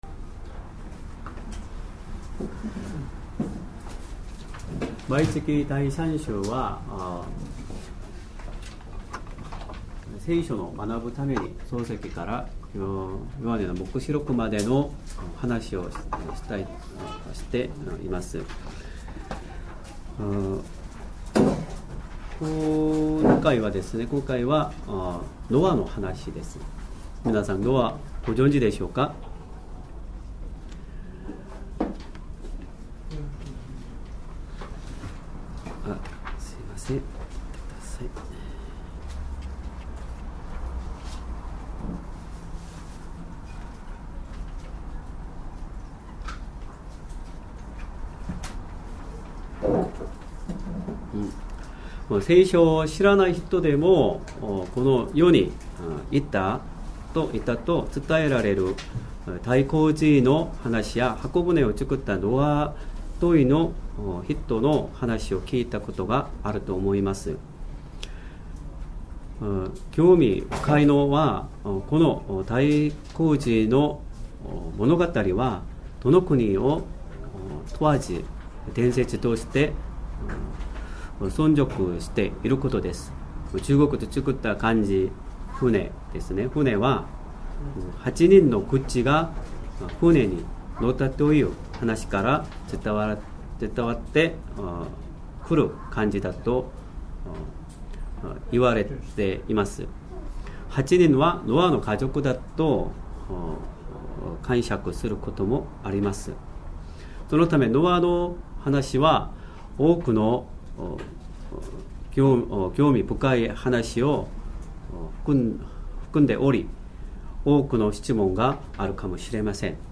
Sermon
2023年 9月17日 主日礼拝 説教 箱舟を作るノアの信仰 創世記 6：9～22 6:9 これはノアの歴史である。